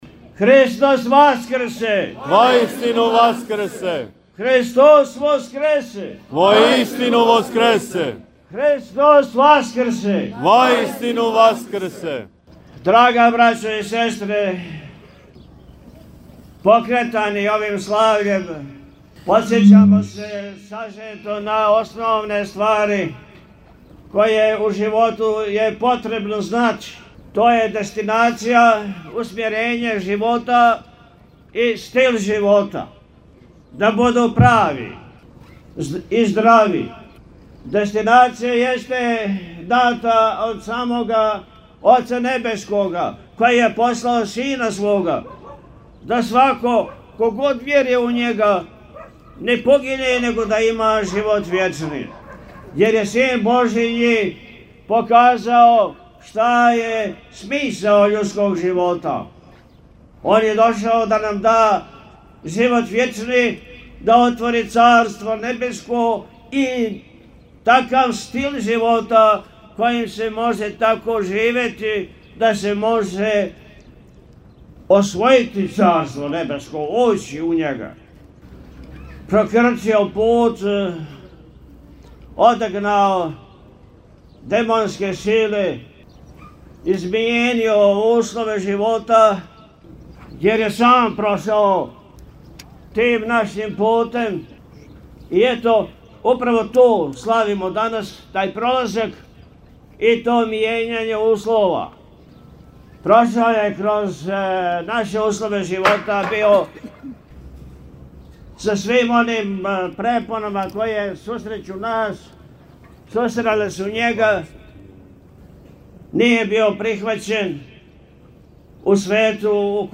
Васкршња литија у Прибоју - Eпархија Милешевска
Након вечерње службе кренула је свечана Васкршња литија у којој је учествовао велики број верног народа. Литија је прошла градским улицама узносећи молитве и благодарност Богу и певајући духовне песме у славу Божију.
Priboj-Vasksnja-Litija.mp3